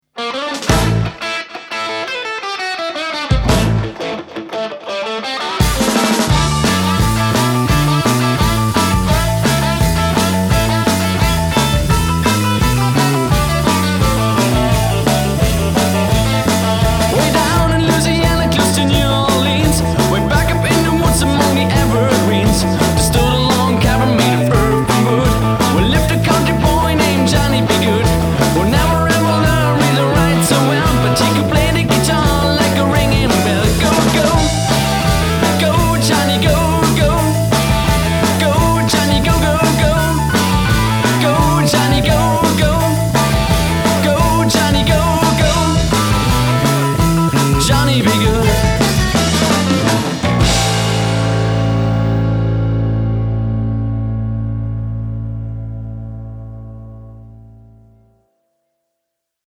• Rockband
• Coverband